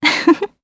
Isolet-Vox_Happy2_jp.wav